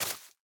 assets / minecraft / sounds / block / moss / break5.ogg